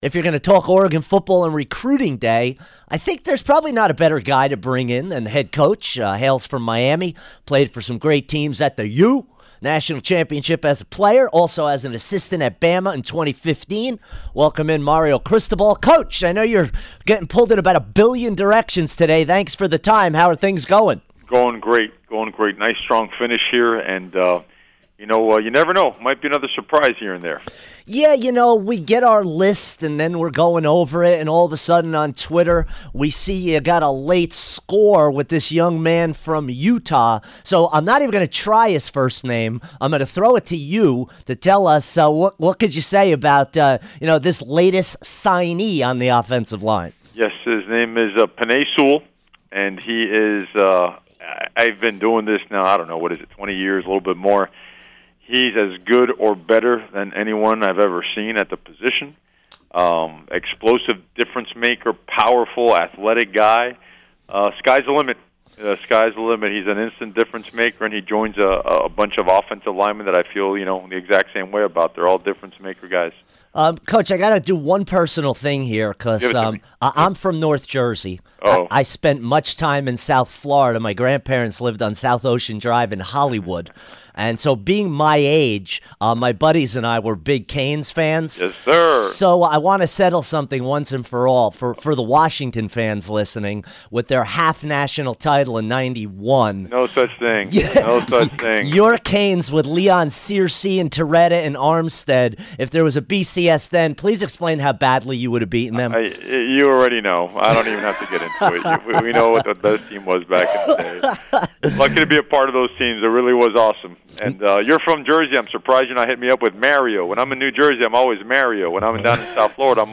Mario Cristobal Signing Day Interview 2-7-18